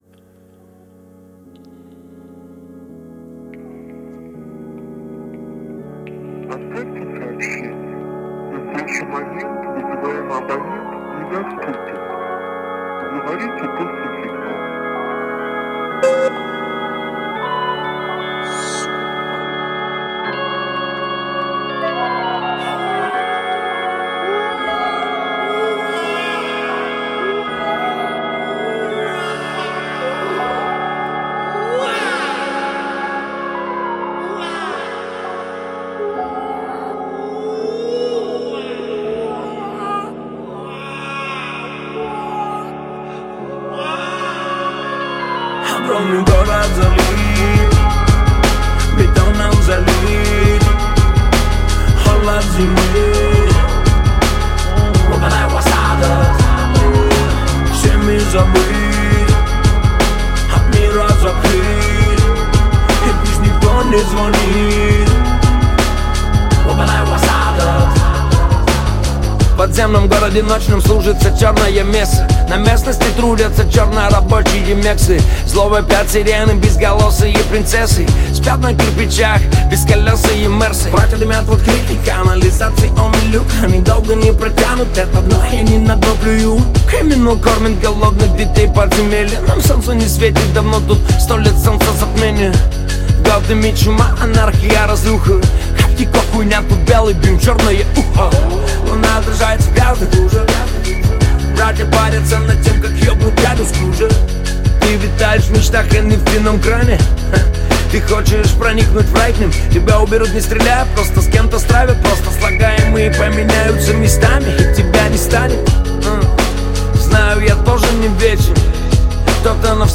Русский рэп
Жанр: Русский рэп / Хип-хоп